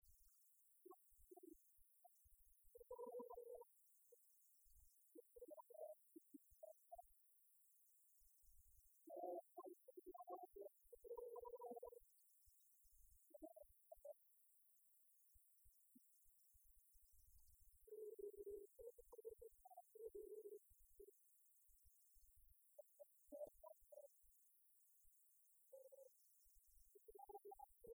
Genre strophique
Concert de la chorale des retraités
Pièce musicale inédite